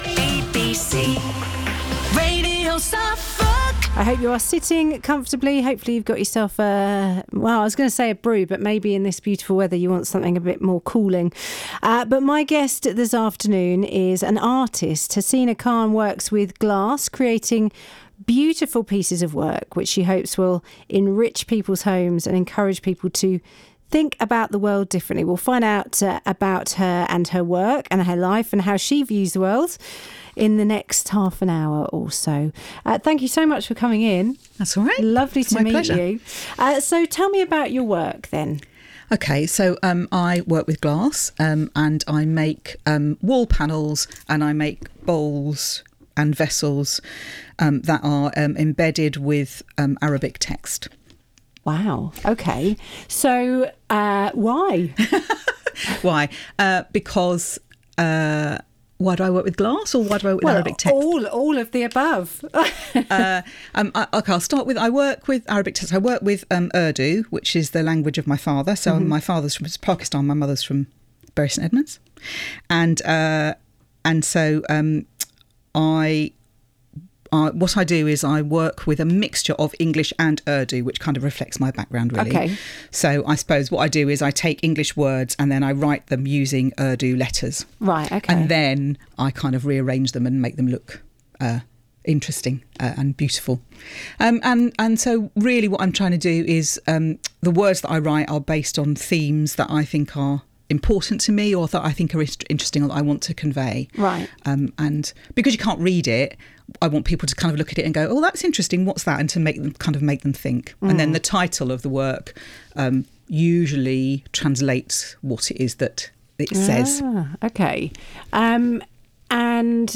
Radio interview
Radio-Suffolk-Interview-June-2023.mp3